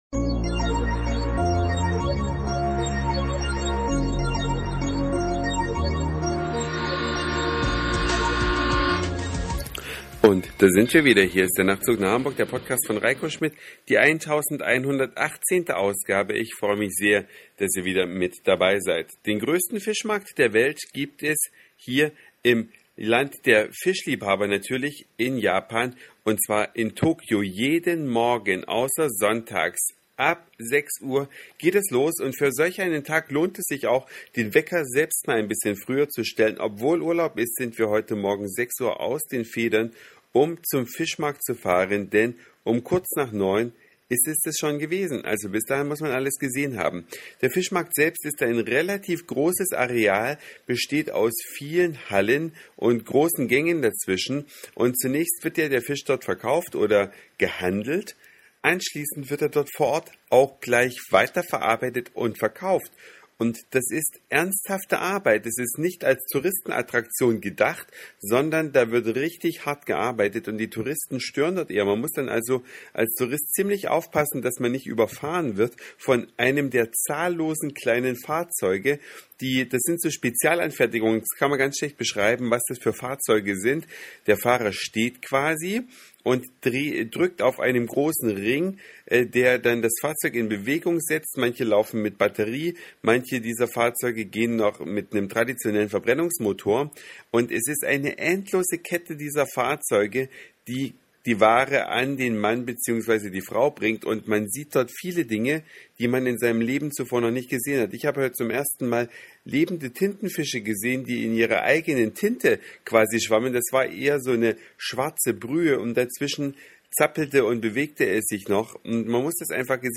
Eine Reise durch die Vielfalt aus Satire, Informationen, Soundseeing und Audioblog.